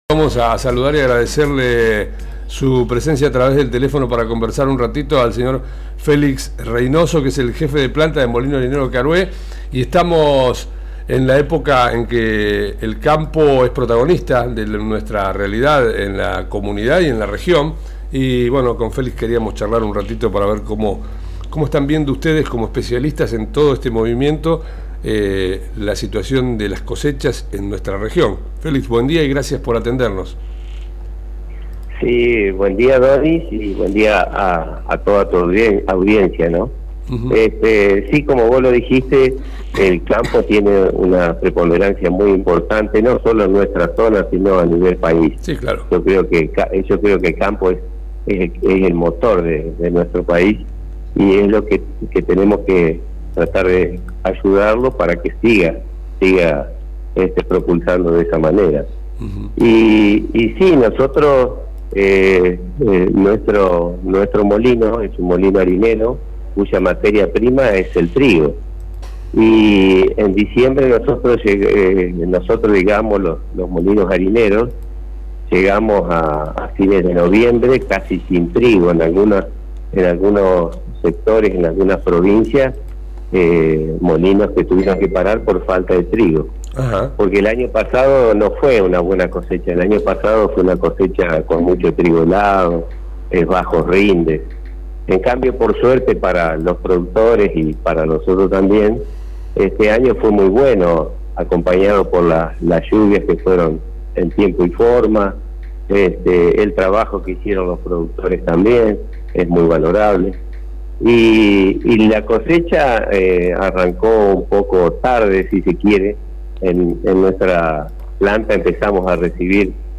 nos explicó en una entrevista telefónica las bondades de la actual cosecha en nuestra región y las expectativas que despierta.